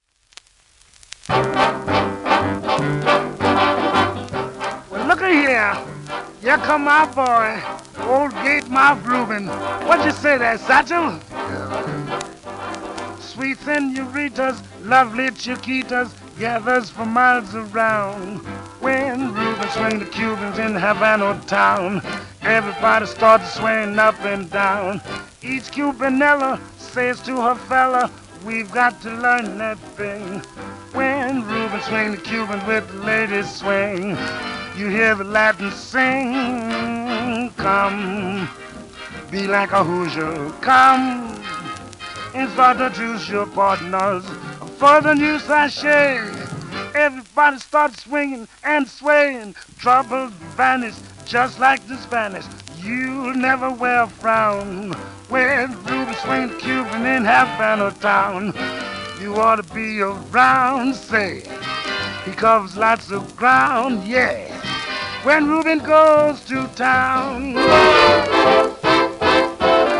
1936年N.Y録音